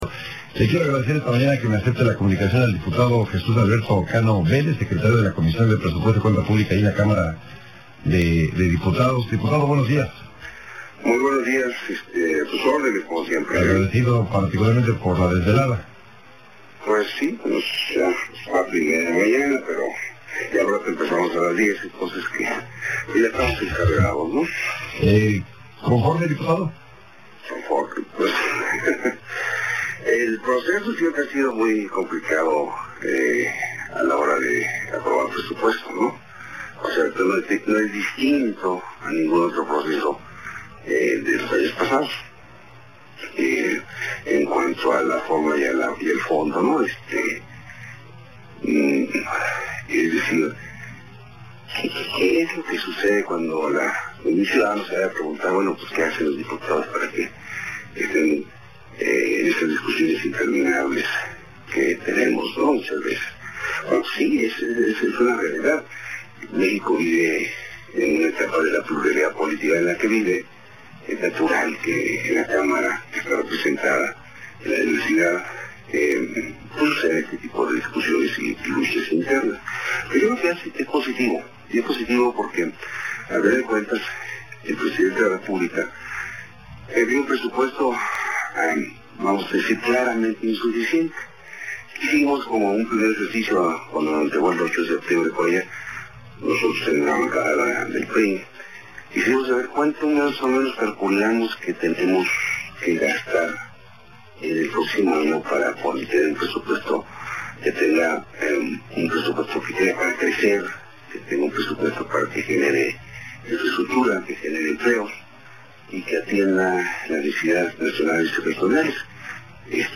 15-11-10 Entrevista de Radio en Formato 21